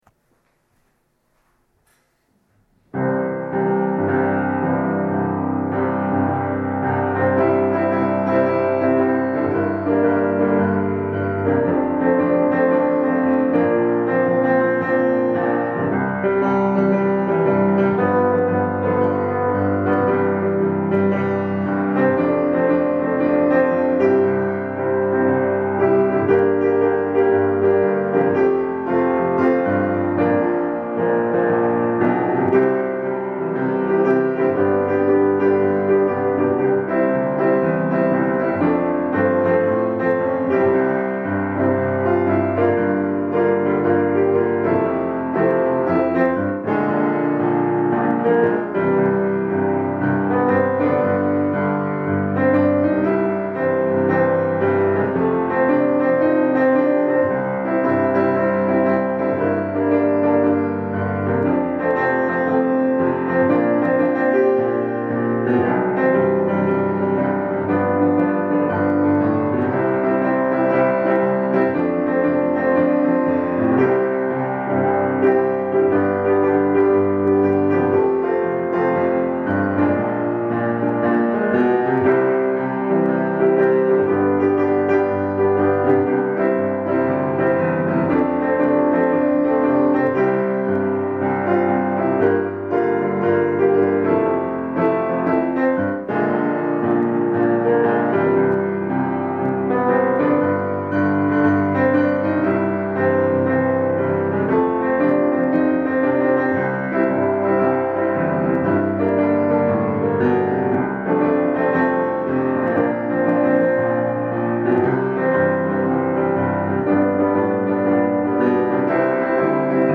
Dobová hudba ve formátu MP3